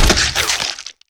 Dynia_Destroy.wav